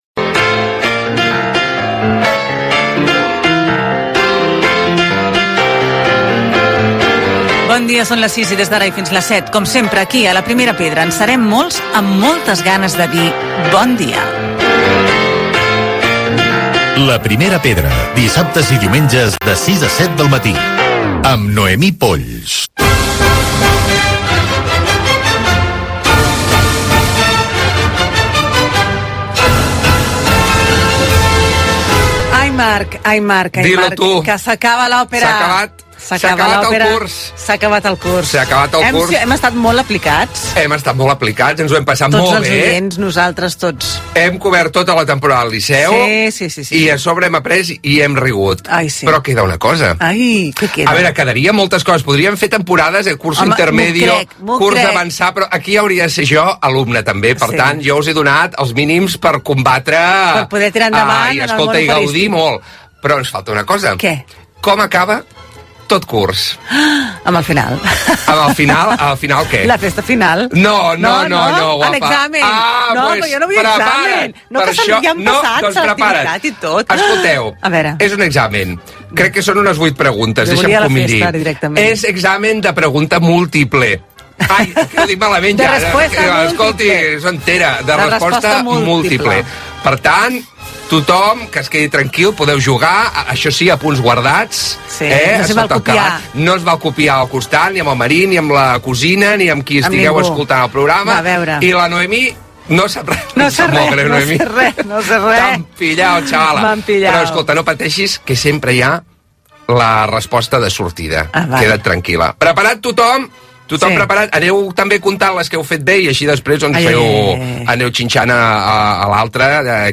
Careta d'entrada, secció de divulgació de l'òpera i fets significatius d'aquella data amb un frgament radiofònic extret de l'Arxiu Sonor de la Ràdio a Catalunya de la UAB